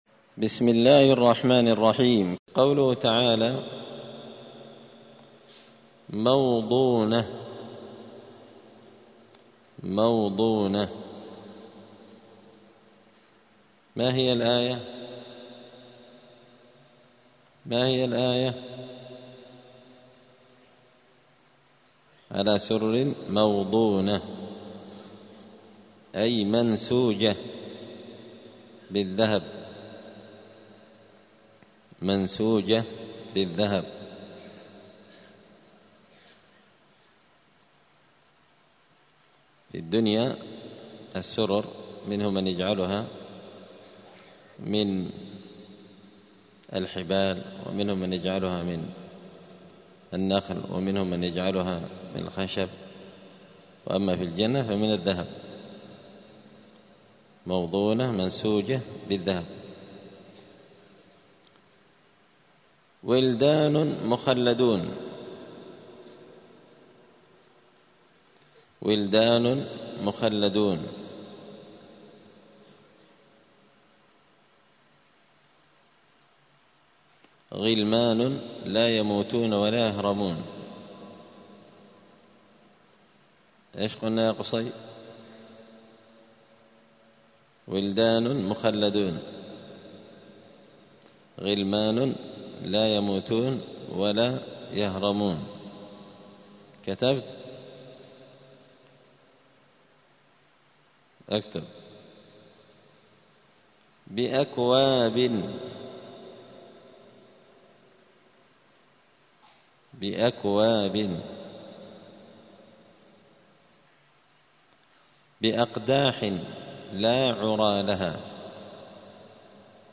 *(جزء الذاريات سورة الواقعة الدرس 187)*